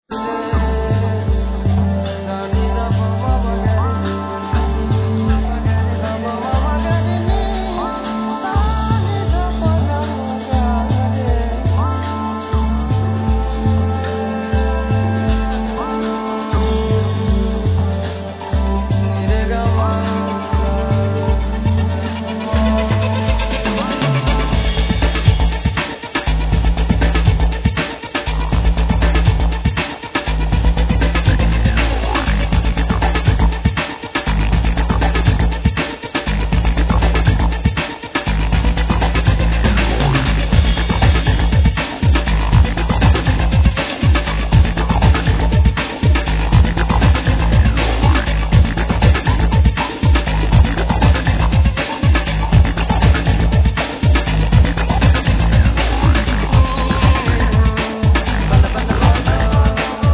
Vocals,Bendir,Spanish claps
Cello,Violin